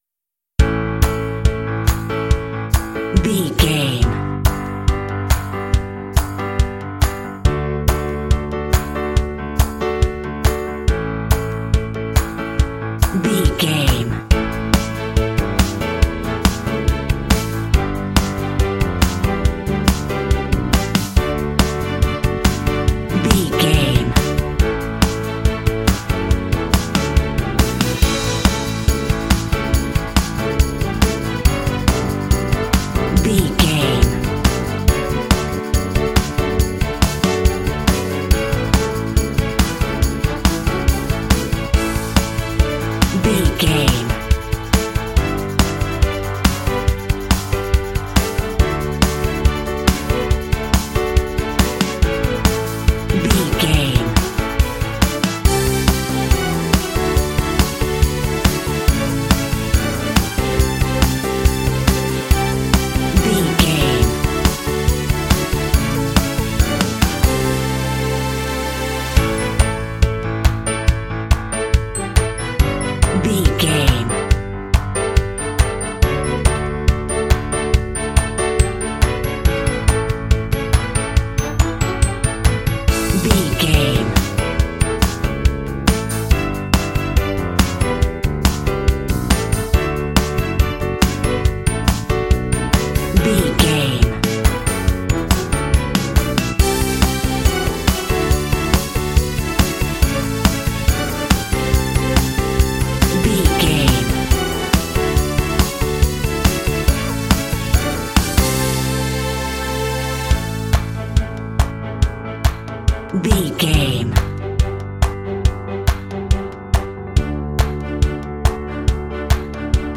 Uplifting
Mixolydian
optimistic
happy
bright
piano
drums
strings
electric guitar
bass guitar
synthesiser
rock
indie